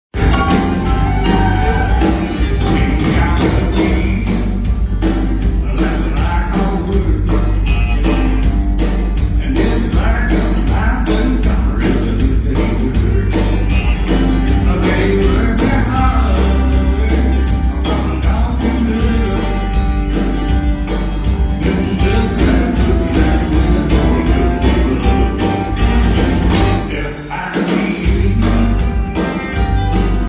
The concert opened with toe-tapping number